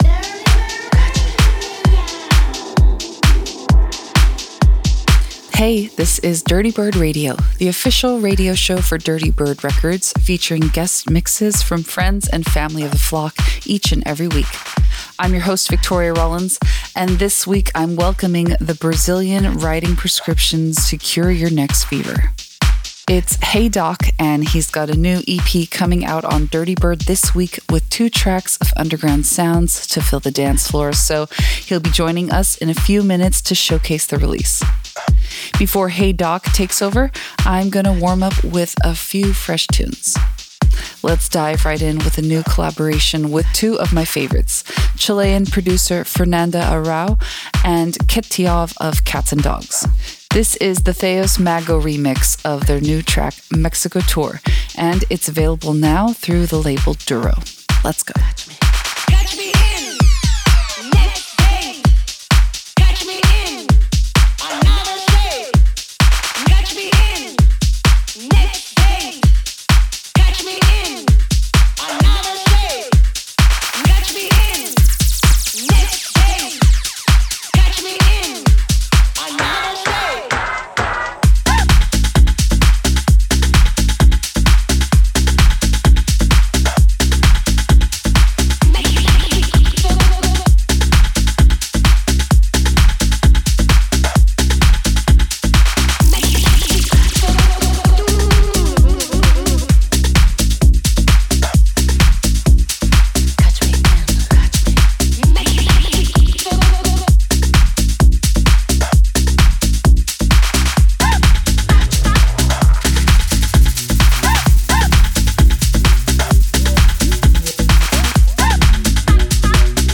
more bass.
guest set